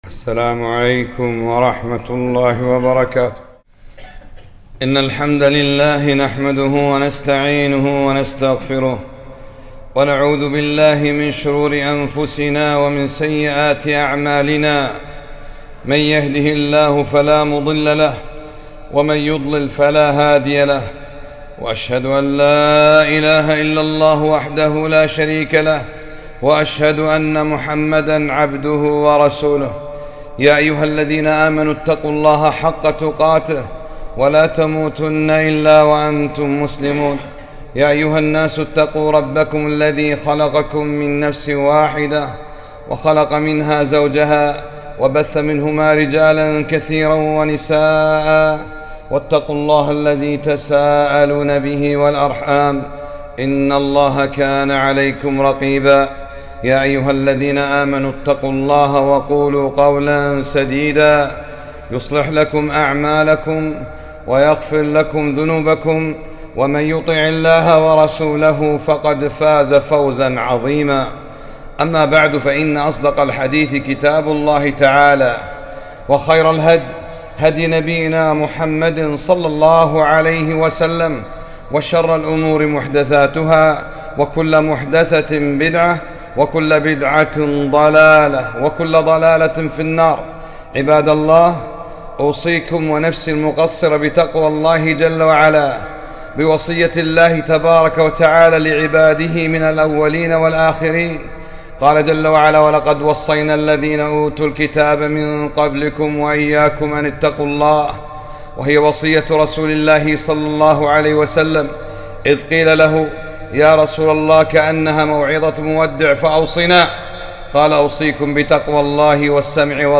خطبة - اصطفى الله الصحابة كما اصطفى النبي ﷺ